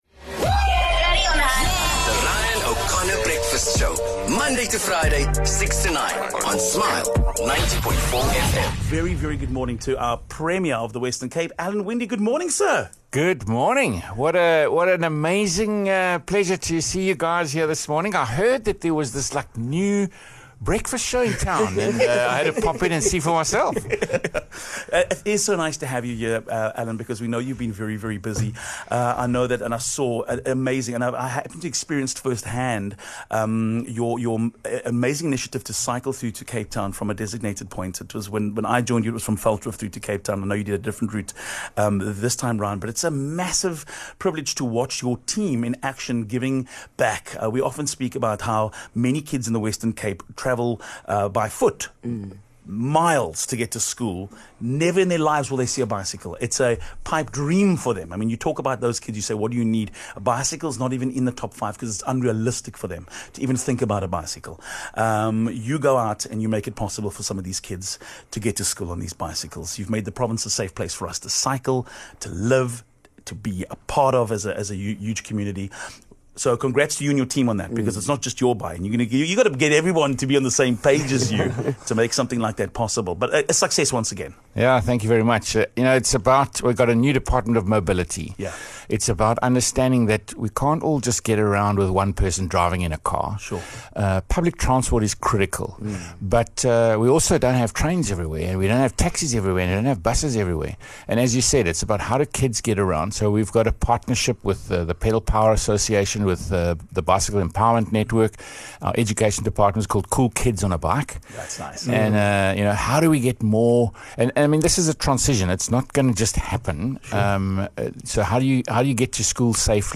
Hearing about a brand new morning radio show in Cape Town, Premier Alan Winde simply needed to pop in for a visit.